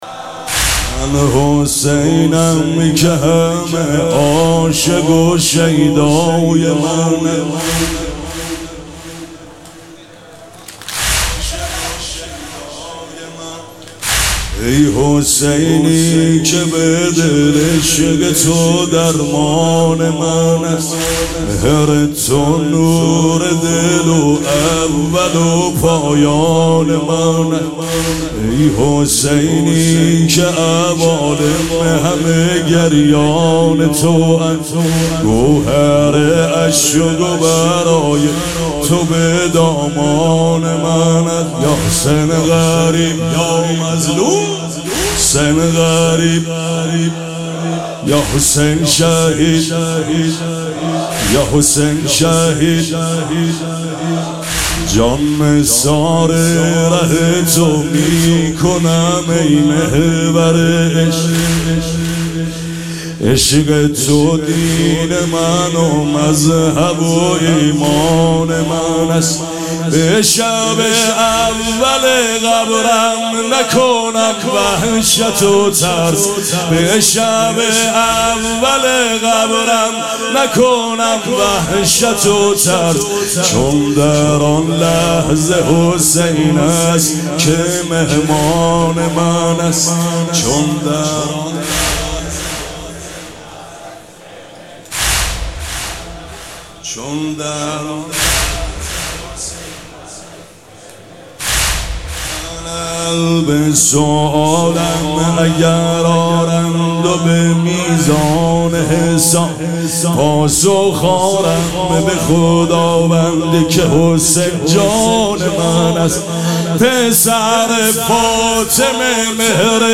شب دوم مراسم عزاداری اربعین حسینی ۱۴۴۷
مداح
مراسم عزاداری اربعین حسینی